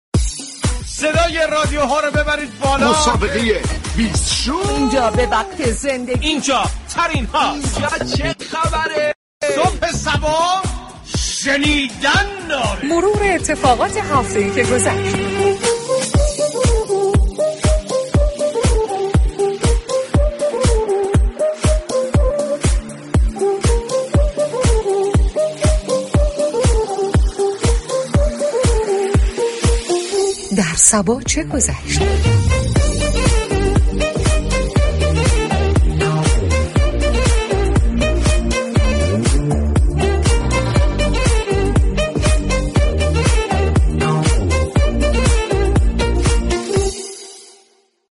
به گزارش روابط عمومی رادیو صبا ، این شبكه در هفته كه گذشت ویژه برنامه هایی را به مناسبت هفته دفاع مقدس و روز اربعین تقدیم مخاطبان كرد كه در برنامه "در صبا چه گذشت " با پخش بخش هایی از برنامه و گفتگو با عوامل آنهابه نقد و بررسی این برنامه ها می پردازد.